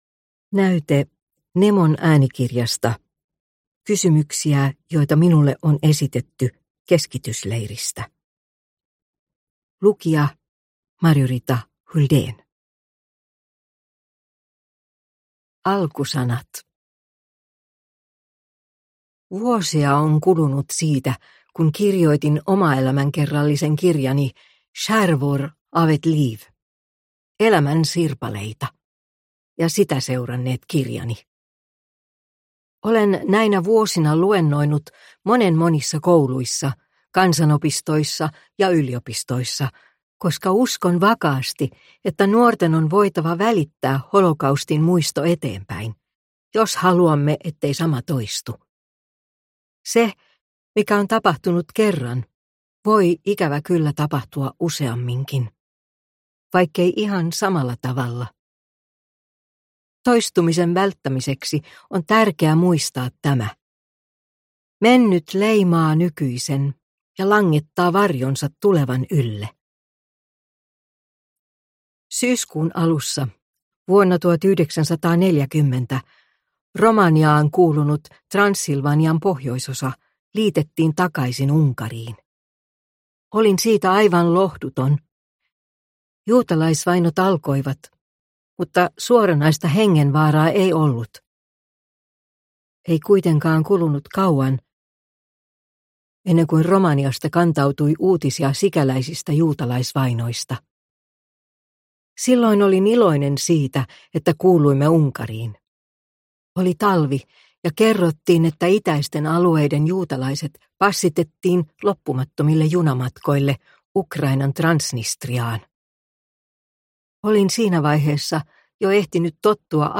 Kysymyksiä joita minulle on esitetty keskitysleiristä – Ljudbok – Laddas ner